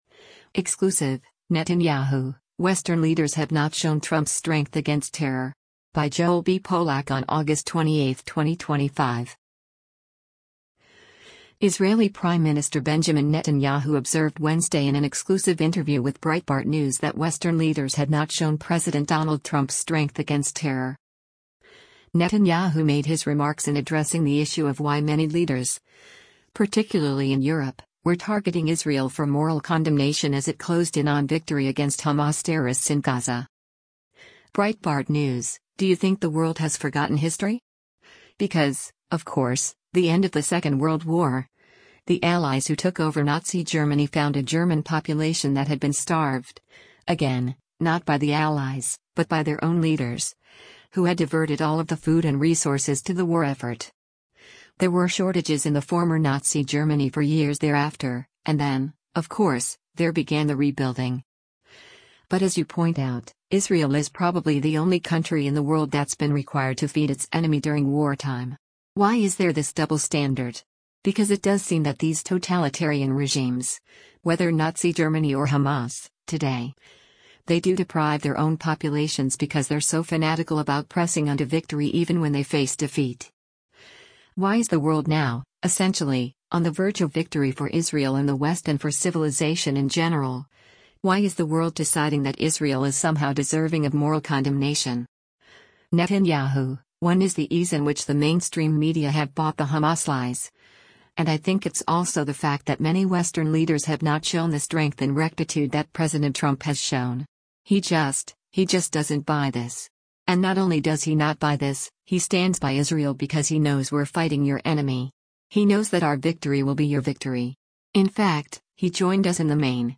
Israeli Prime Minister Benjamin Netanyahu observed Wednesday in an exclusive interview with Breitbart News that western leaders had not shown President Donald Trump’s strength against terror.